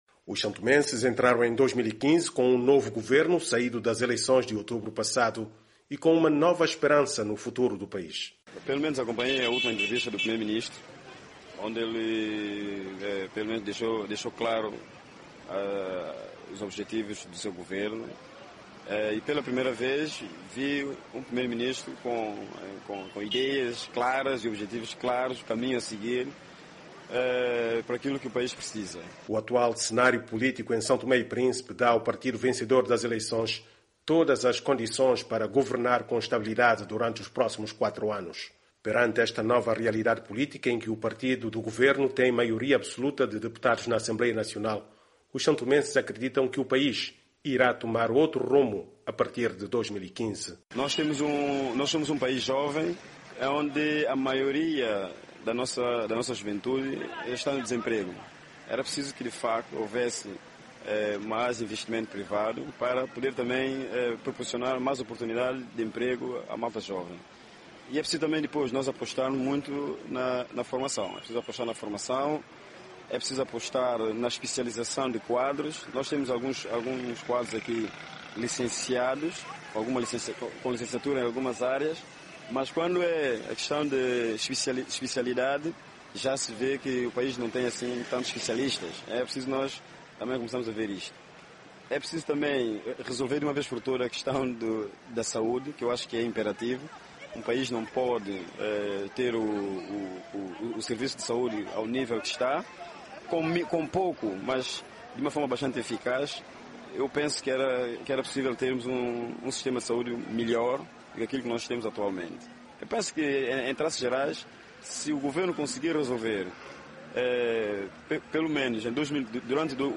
A saúde também é um problema que urge resolver e que alguns entrevistados acreditam ser possível, demonstrado-se bastante optimistas.